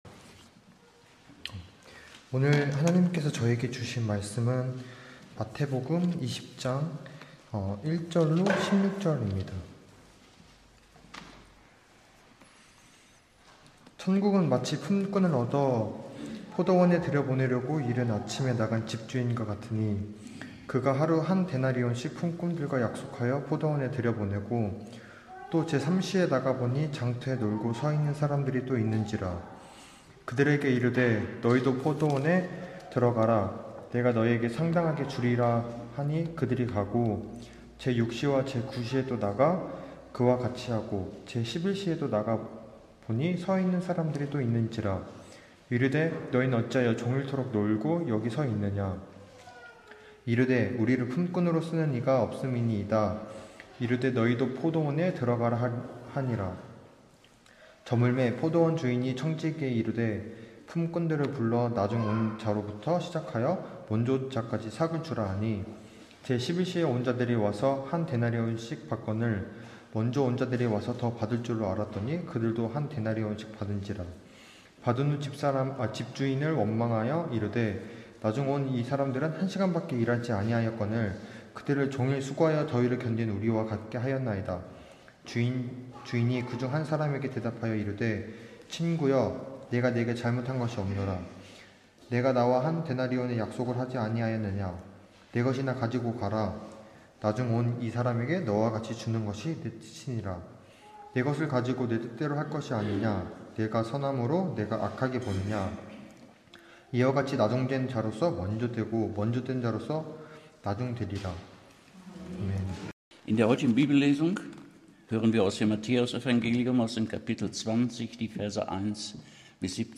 2022년 11월 13일 주일예배 말씀 선하신 하나님(마태복음 15장 1절 - 16절)